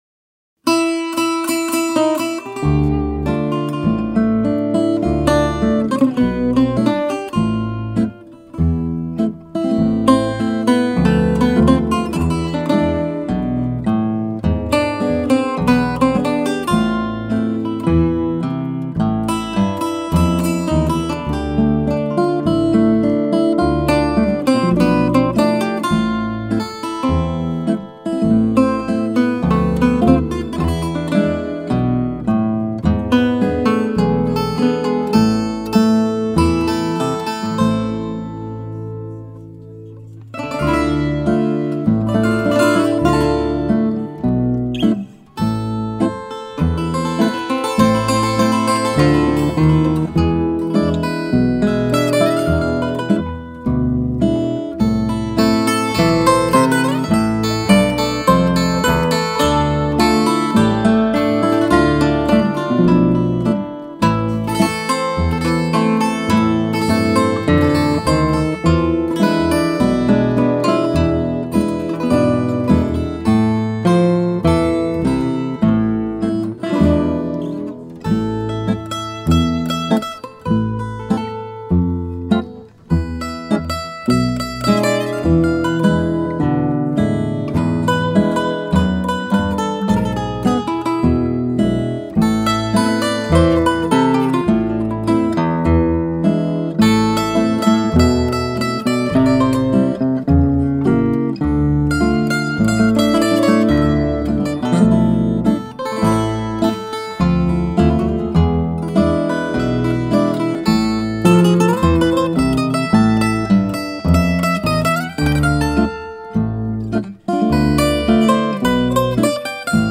Gravação de 11 temas - Fado Tradicional e Fado Canção